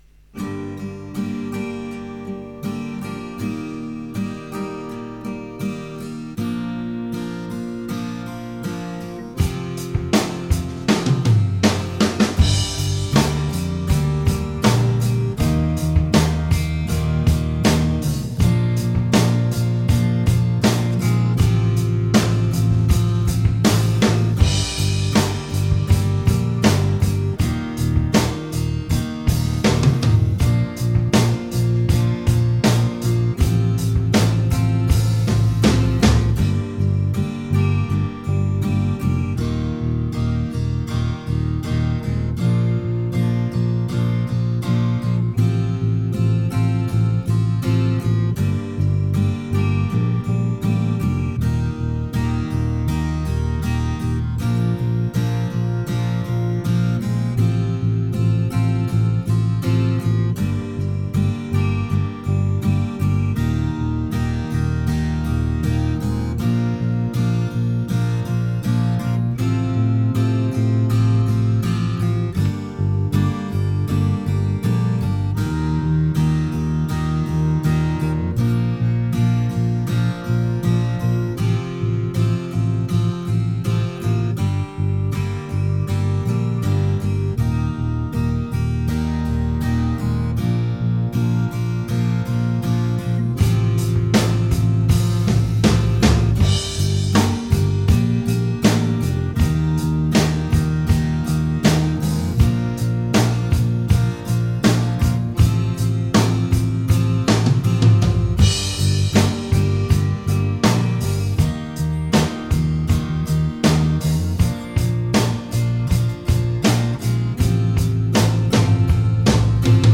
Band in a Box Guitar Backing Tracks Download
Band-in-a-Box Backing Tracks in different keys.